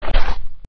bite.ogg